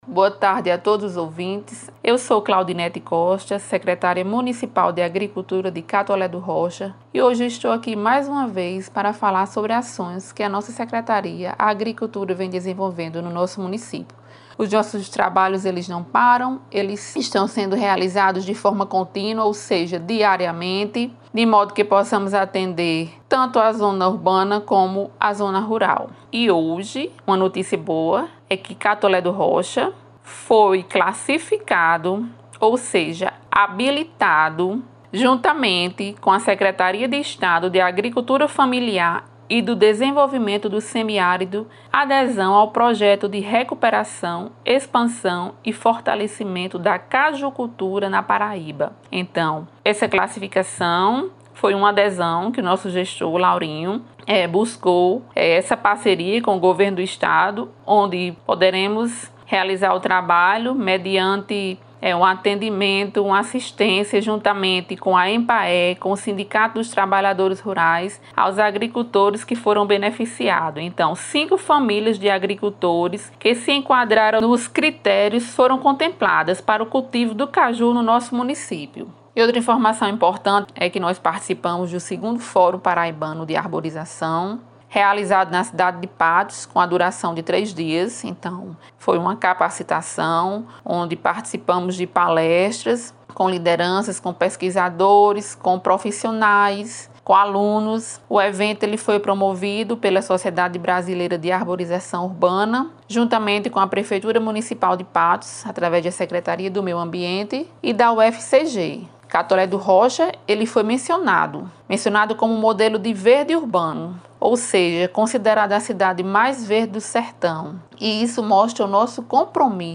Ouça a secretária de agricultura Claudinete Costa: